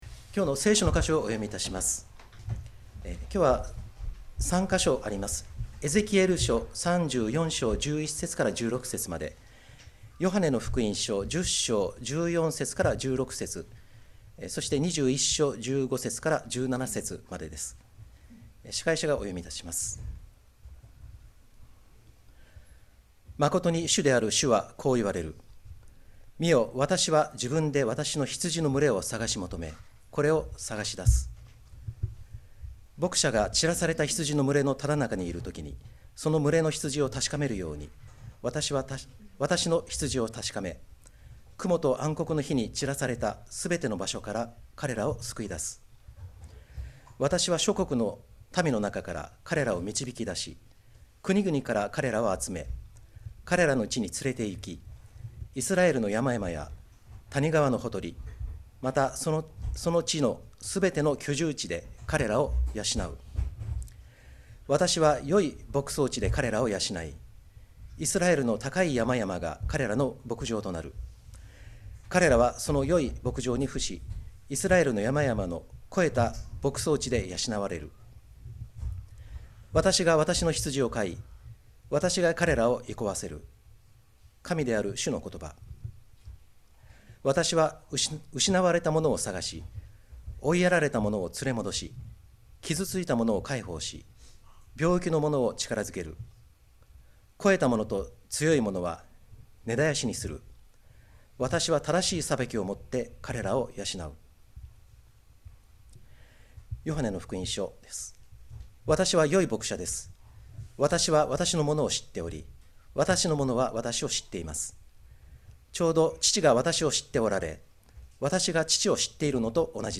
2025年5月25日礼拝 説教 「神の羊を探し養う大牧者」 – 海浜幕張めぐみ教会 – Kaihin Makuhari Grace Church